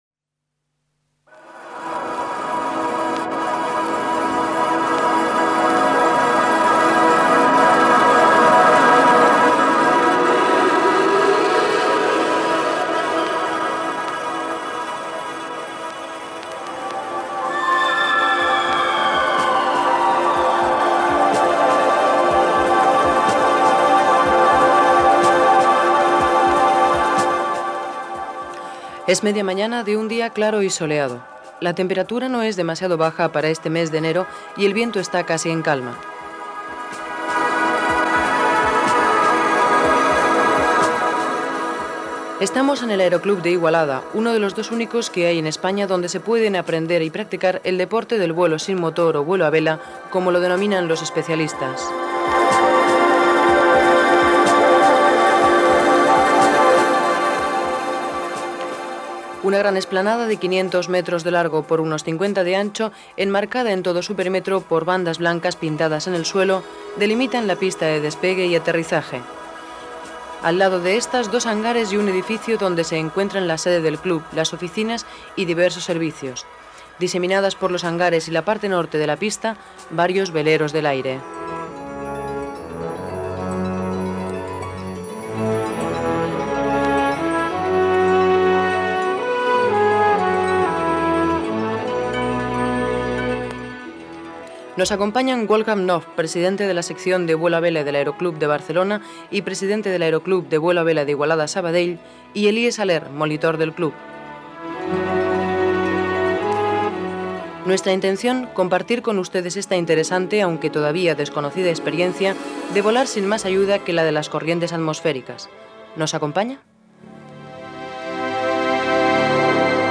Reportatge sobre el vol sense motor fet a l'aròdrom d'Igualda
Informatiu